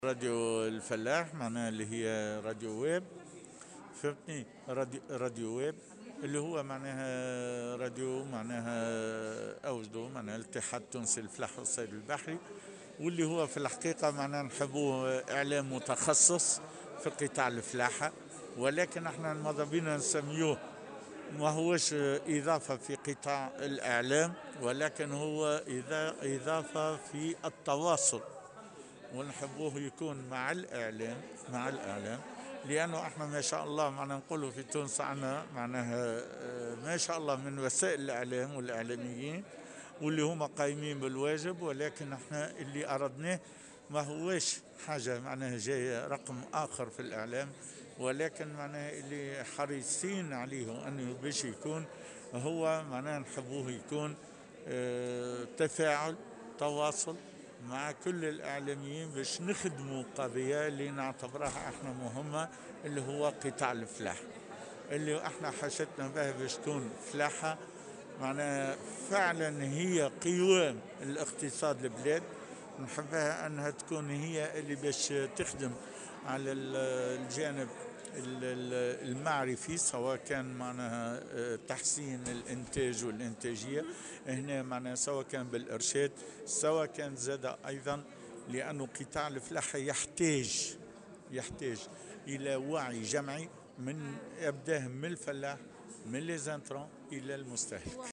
في تصريح لمراسلة "الجوهرة أف أم"